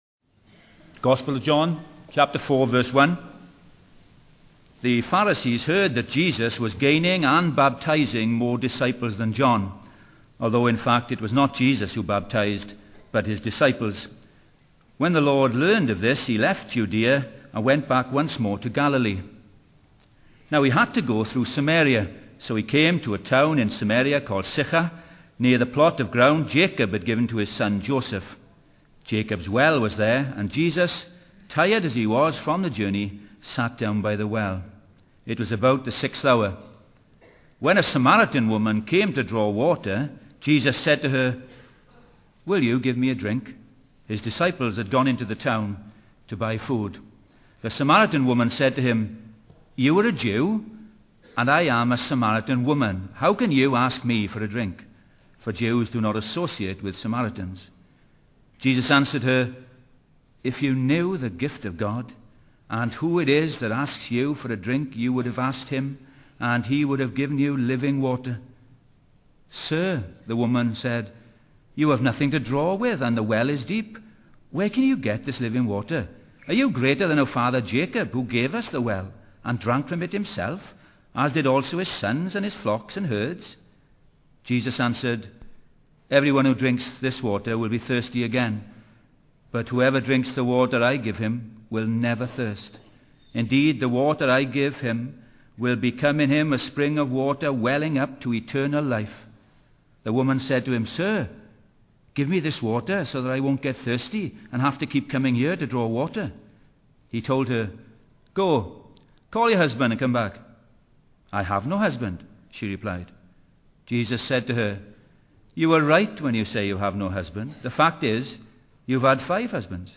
In this sermon, the preacher emphasizes the darkness and emptiness of the world and the need for salvation.